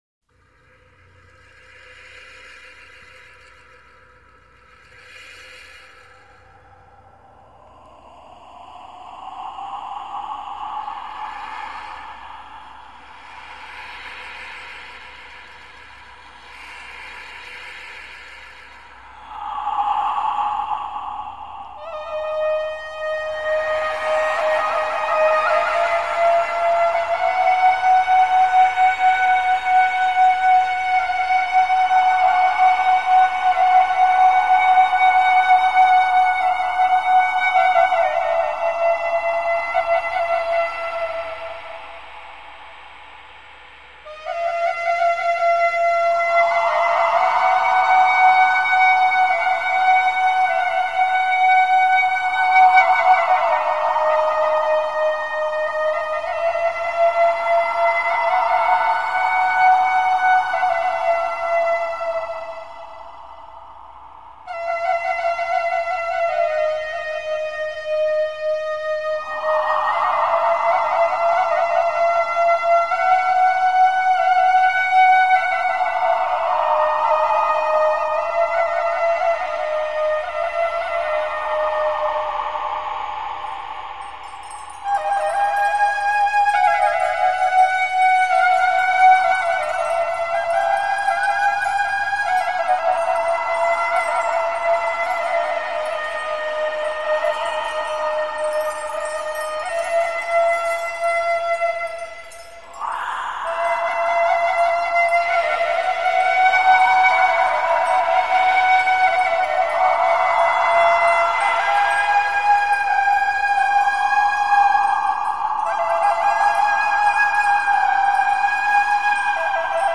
Format: Vinyl, LP, Album
[mexican ambient music]